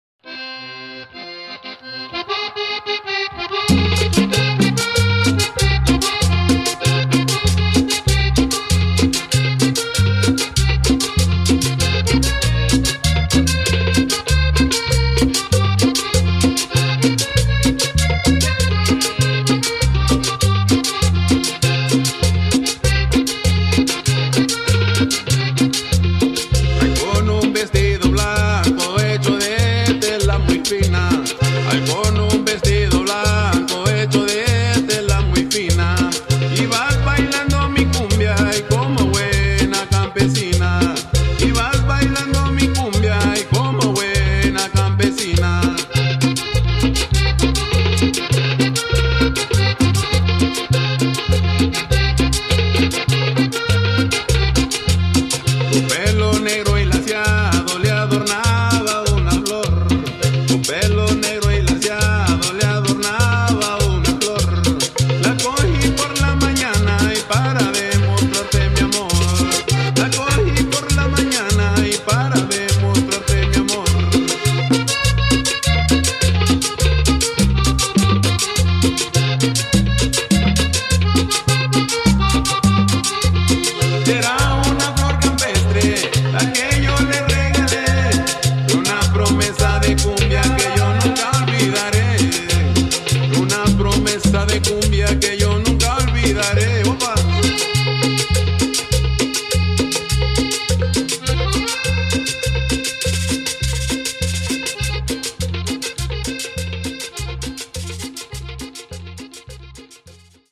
Tags: Cumbia